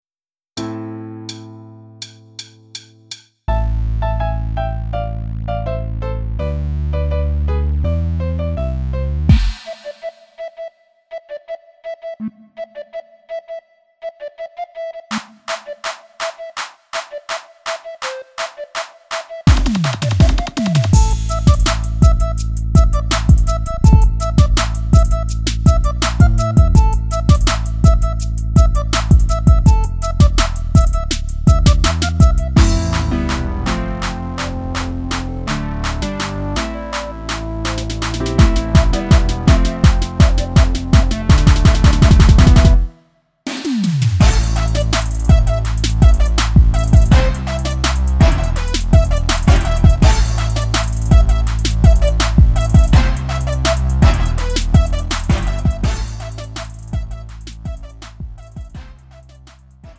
가요
Lite MR